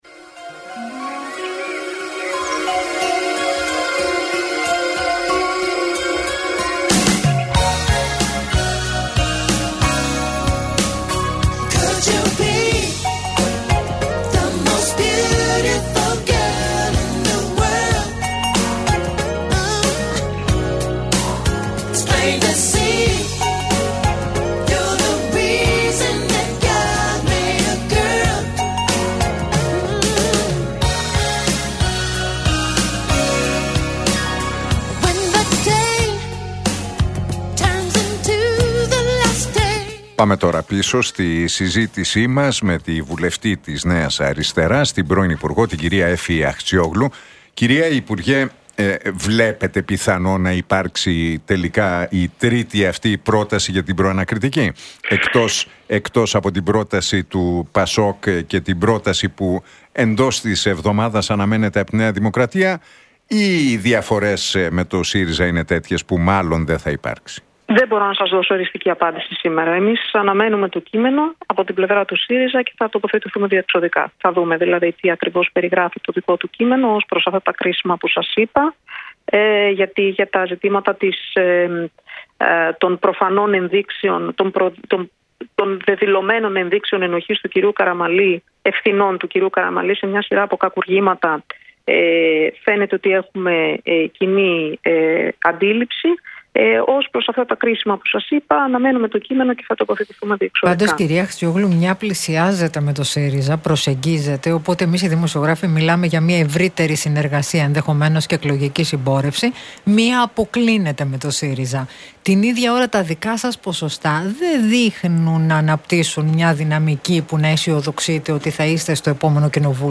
Ακούστε την εκπομπή του Νίκου Χατζηνικολάου στον ραδιοφωνικό σταθμό RealFm 97,8, την Δευτέρα 26 Μαΐου 2025.